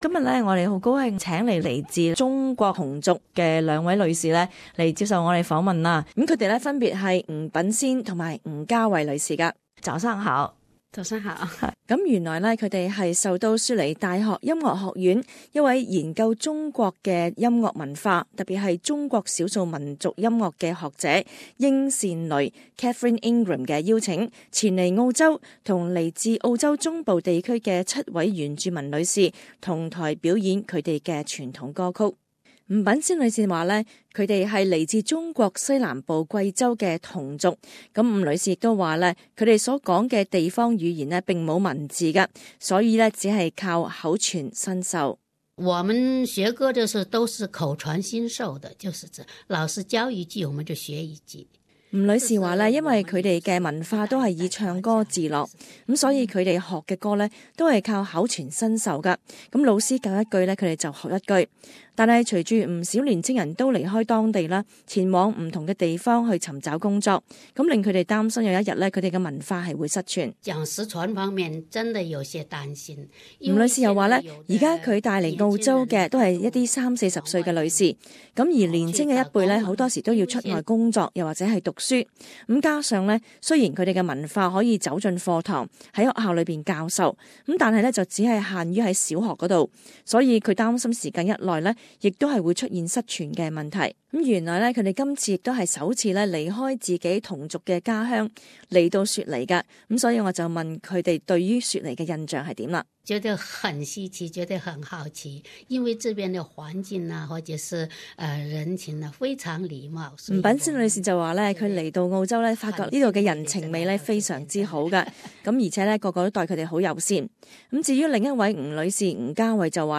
詳情請聽這節社區訪問。